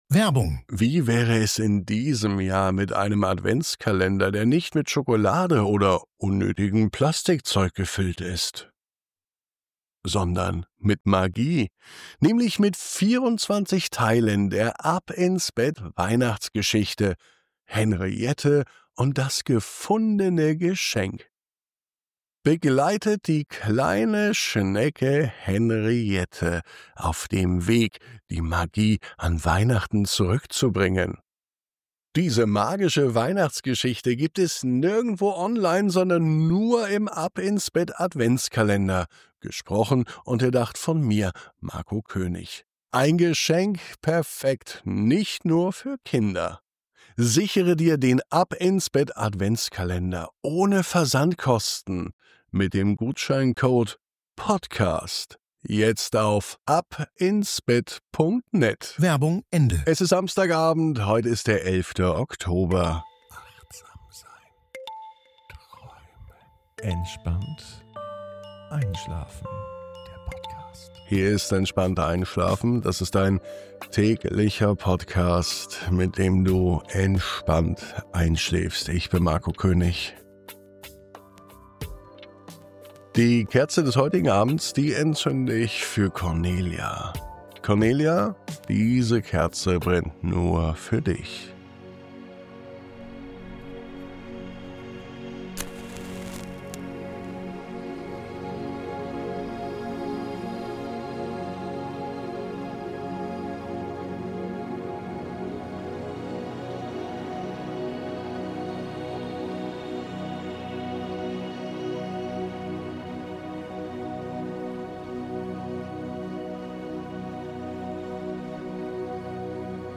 1011_MUSIK.mp3